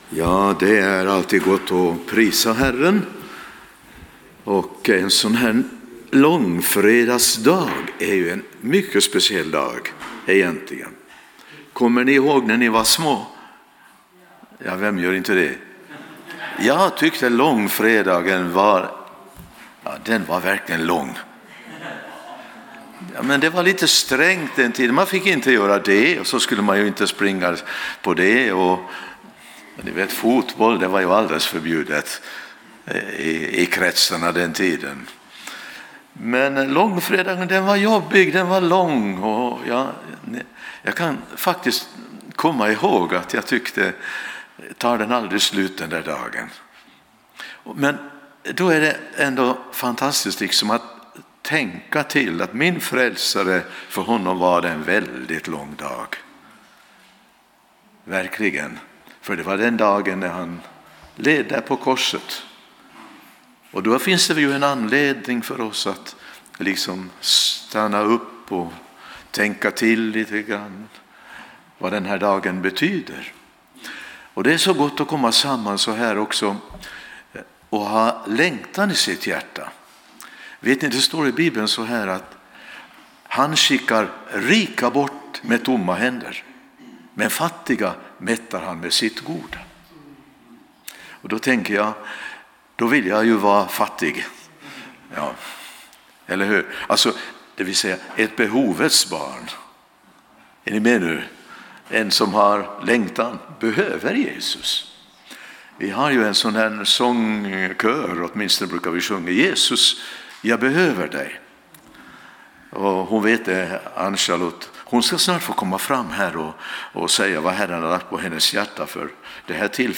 Det finns ett par ikoner uppe till höger där du kan lyssna på ”bara” predikan genom att klicka på hörlurarna eller ladda ner den genom att klicka på pilen.